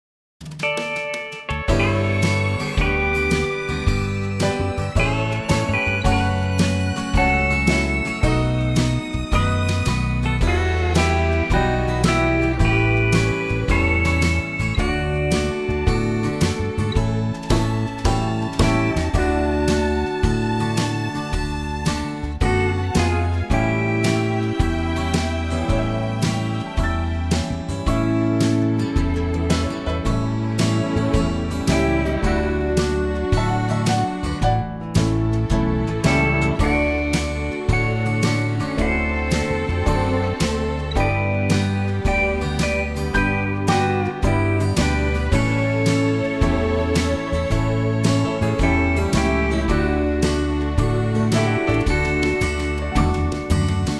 Unique Backing Tracks
key - D - vocal range - C# to E
Here's a nice country arrangement as well!